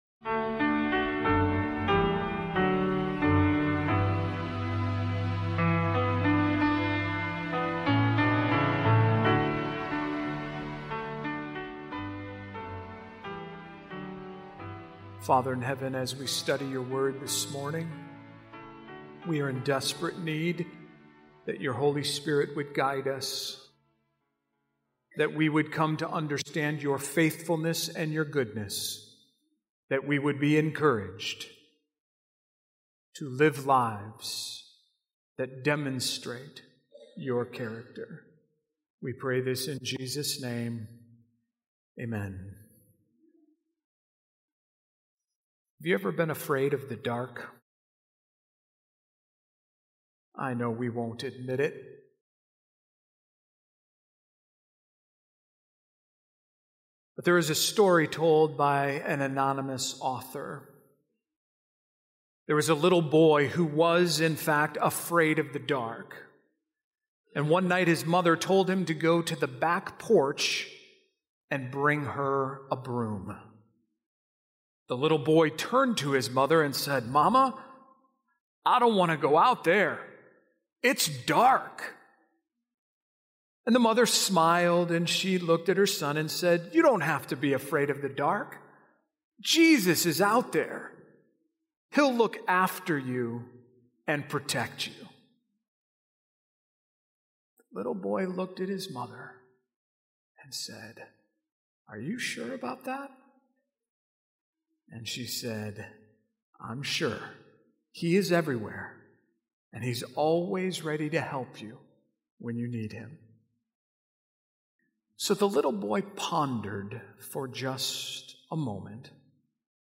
This sermon proclaims God’s Word as the true light that overcomes fear, confusion, and spiritual darkness, calling believers to anchor their faith in Scripture rather than speculation or fear-driven narratives. Through powerful biblical imagery and practical insight, it invites listeners to dwell in God’s presence, wait on Him with courage, and develop a living, authentic faith that endures in uncertain times.